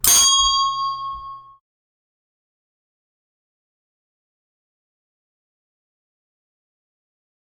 Lift Bell
bell ding elevator handbell lift sound effect free sound royalty free Sound Effects